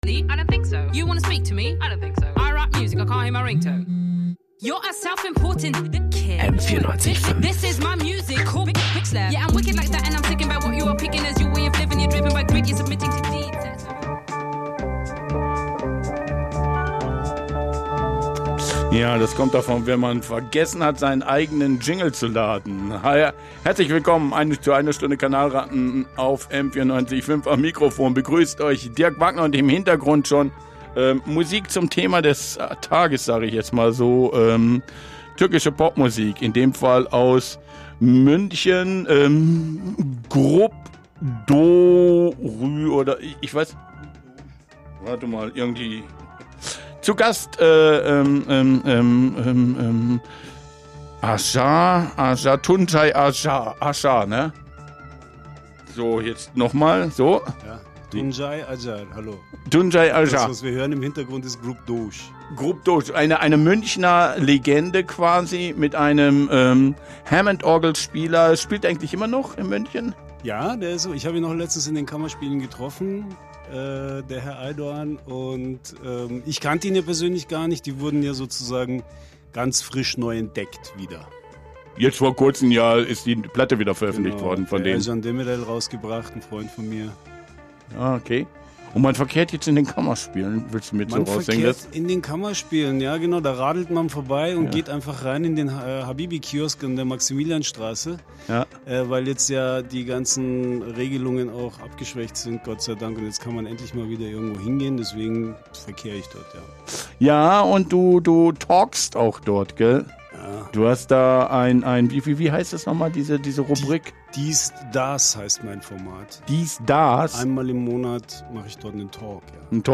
Listening Session